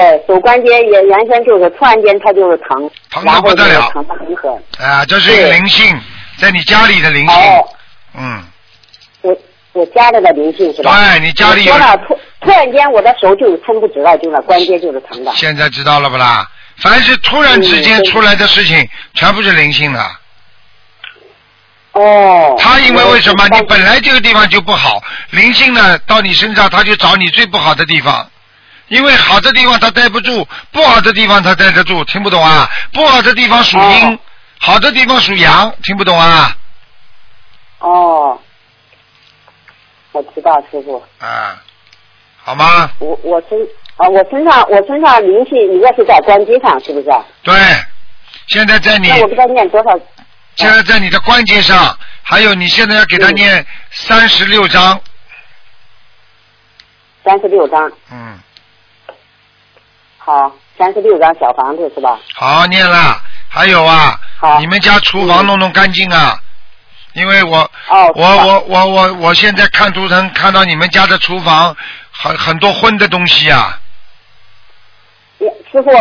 目录：2014年_剪辑电台节目录音集锦